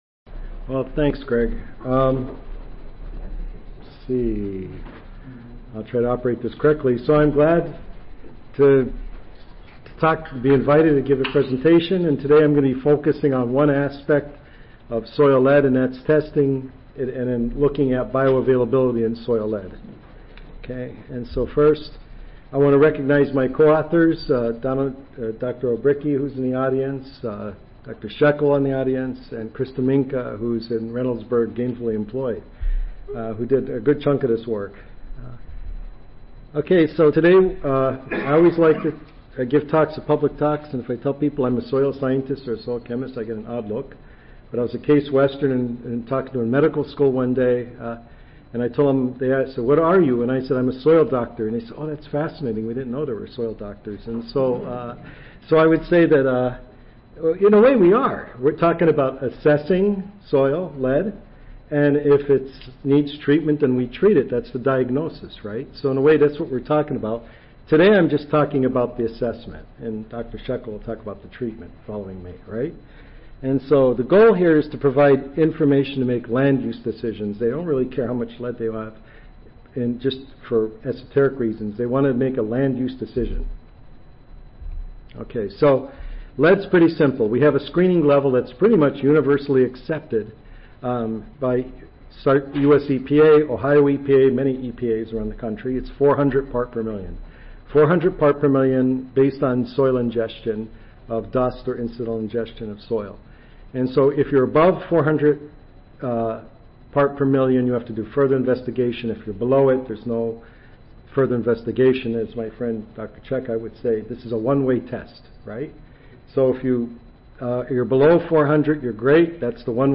U. S. Environmental Protection Agency Audio File Recorded Presentation